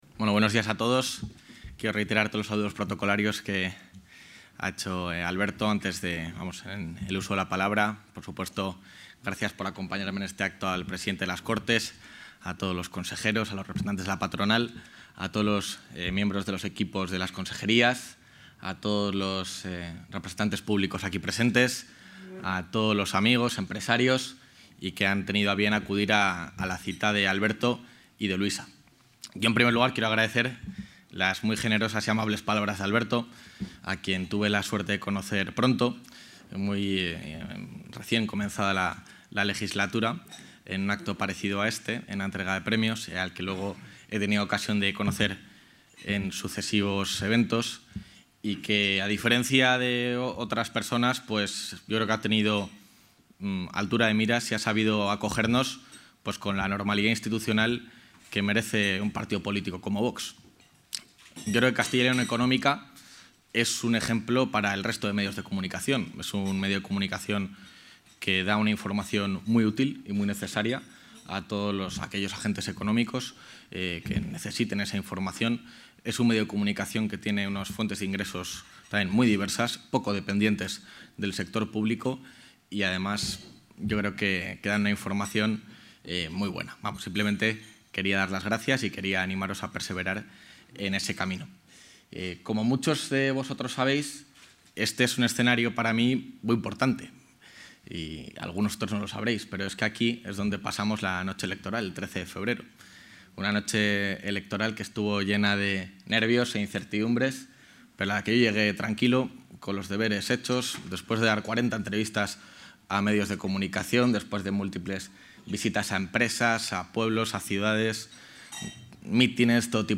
Intervención del vicepresidente.
El vicepresidente de la Junta de Castilla y León, Juan García-Gallardo, ha impartido este martes en el Hotel AC Palacio de Santa Ana de Valladolid la conferencia ‘Pragmatismo en tiempos de dificultad económica’, en un acto organizado por 'Castilla y León Económica'.